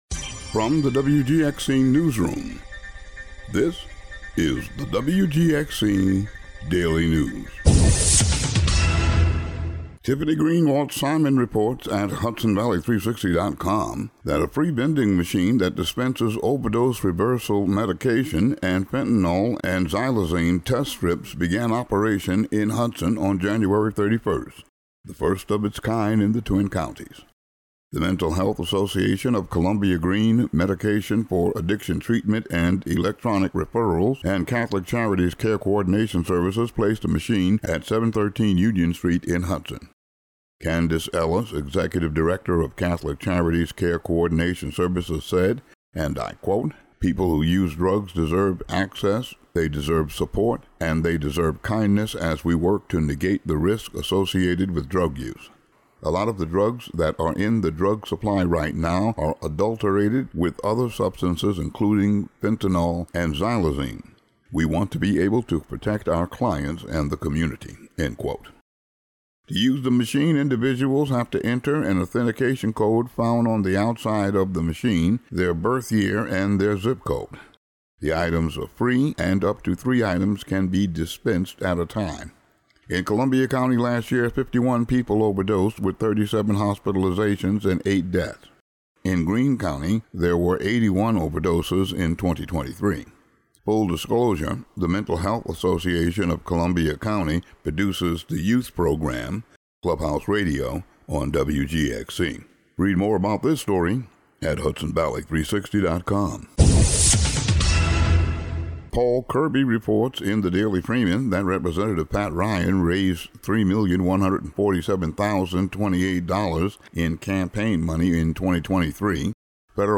Today's audio daily news update.